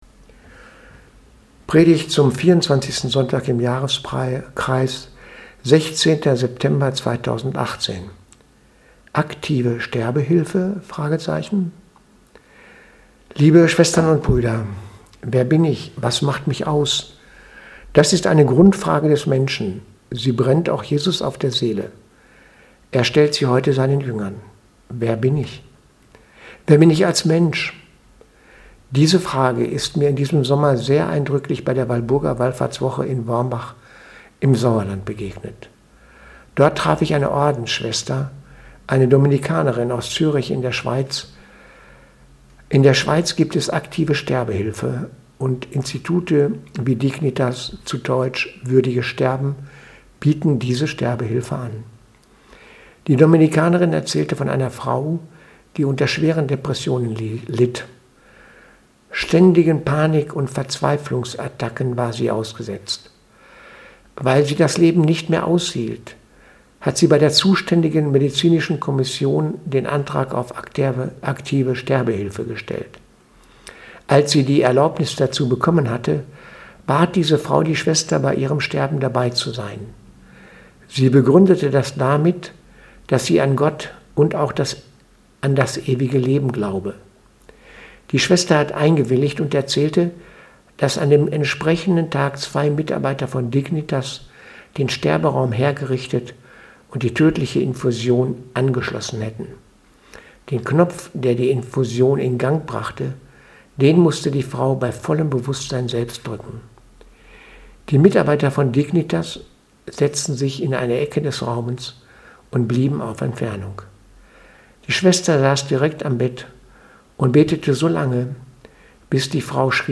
Predigt vom 16.9.2018 – Sterbehilfe